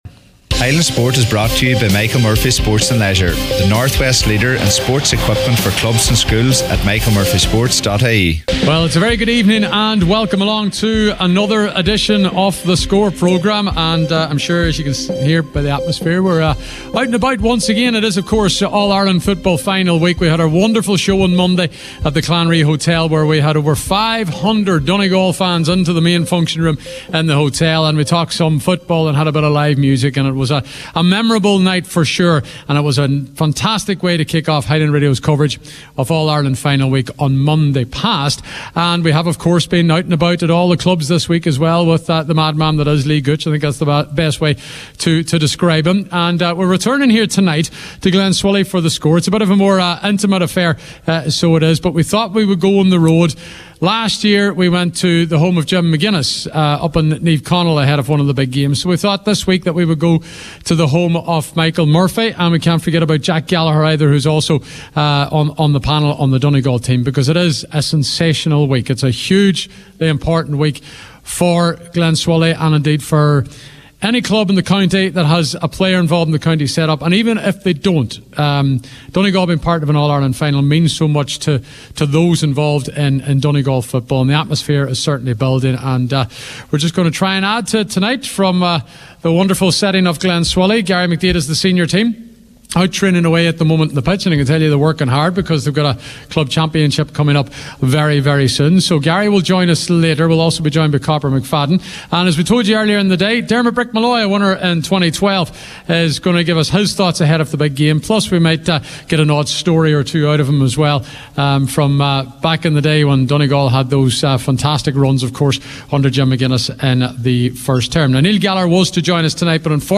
The Score: Live from Glenswilly